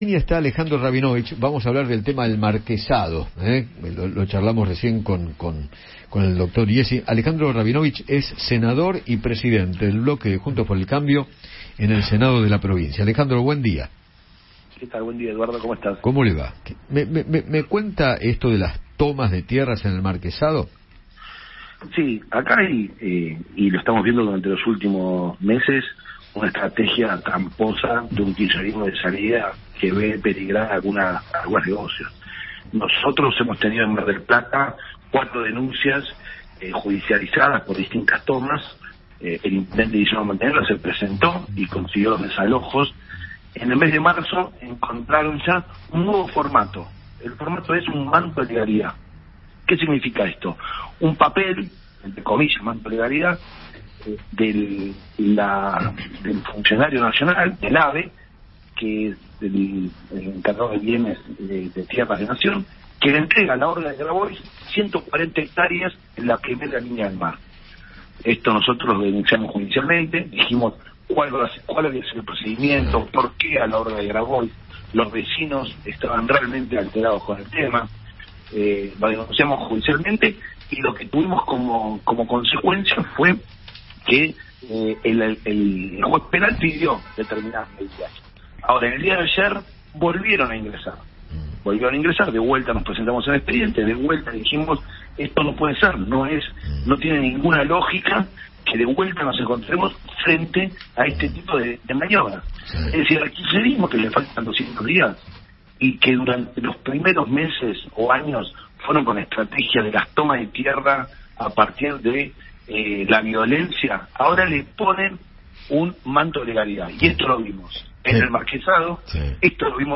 Alejandro Rabinovich, senador bonaerense de Juntos por el cambio, conversó con Eduardo Feinmann sobre el Marquesado.